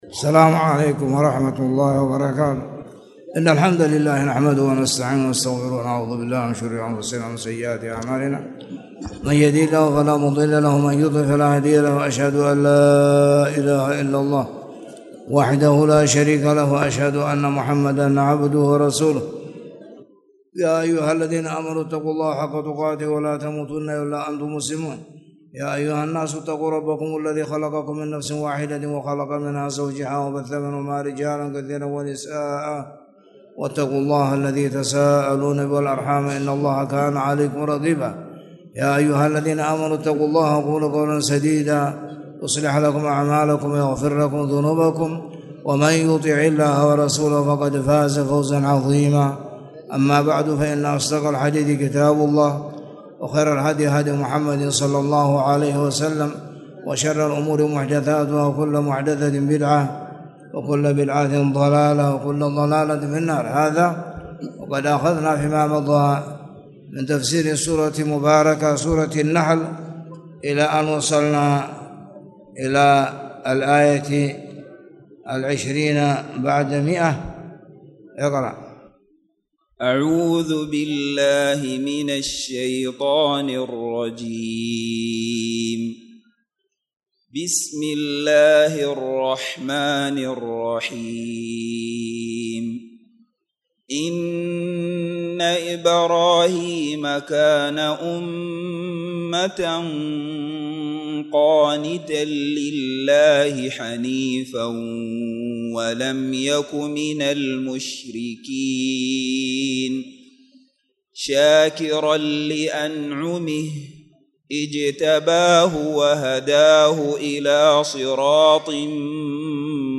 تاريخ النشر ١٦ ربيع الأول ١٤٣٨ هـ المكان: المسجد الحرام الشيخ